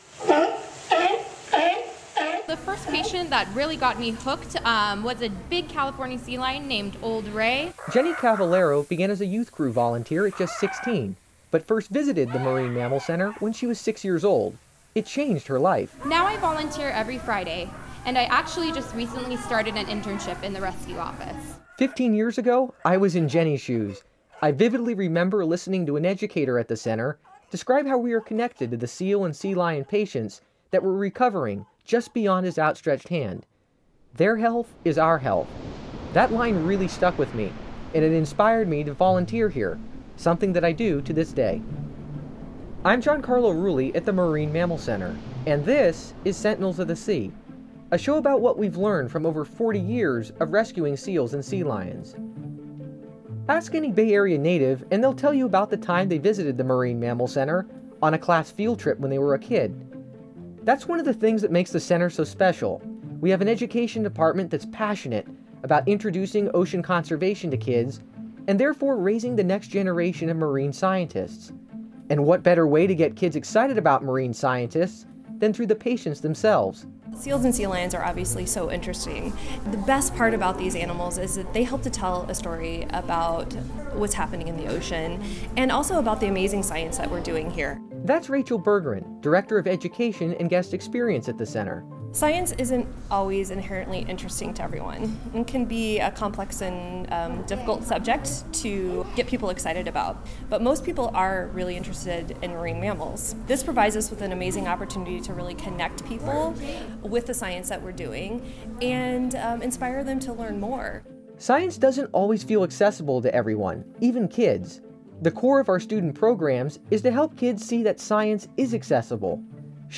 Featuring: